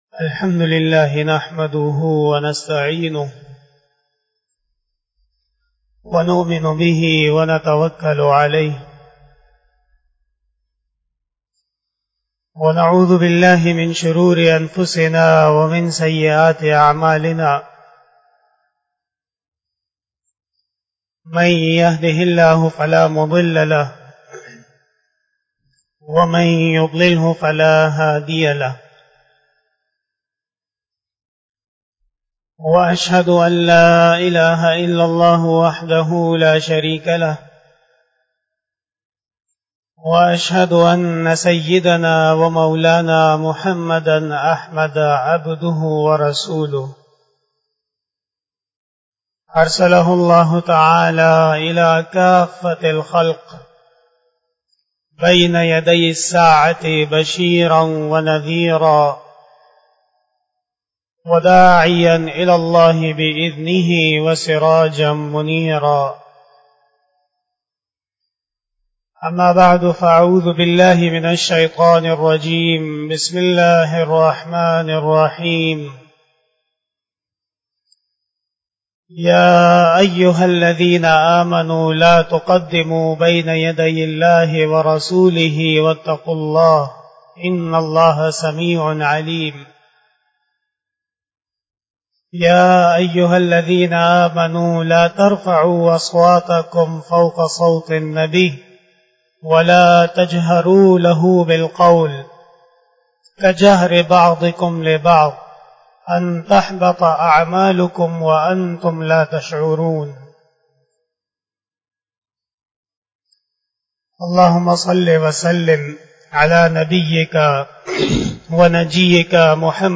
46 BAYAN E JUMA TUL MUBARAK 12 November 2021 (06 Rabi us Sani 1443H)
Khitab-e-Jummah